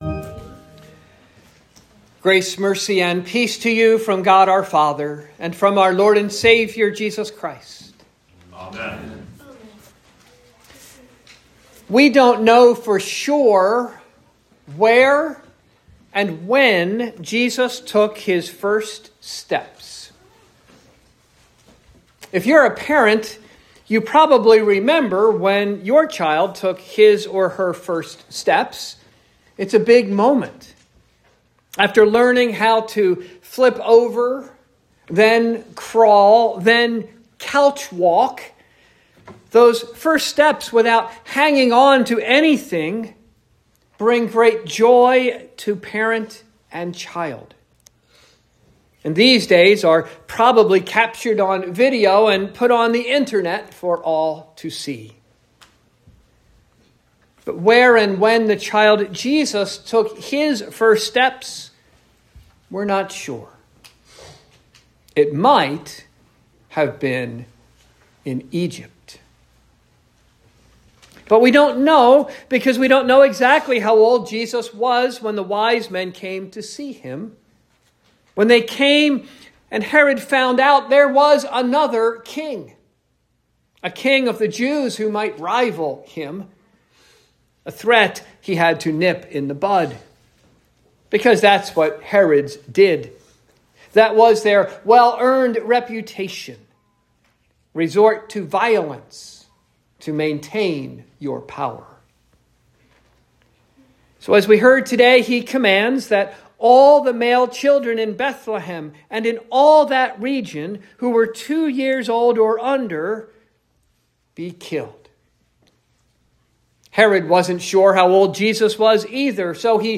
Sermon for the First Sunday in Lent